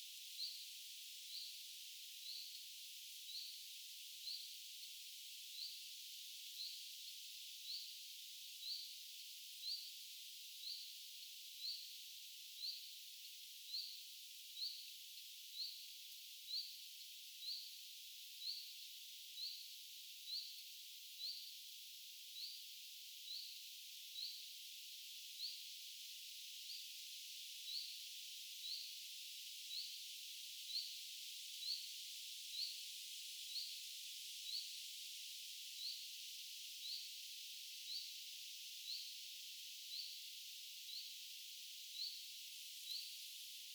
ilm_sellainen_hyit-tiltaltti.mp3